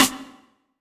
Snr Afrowet.wav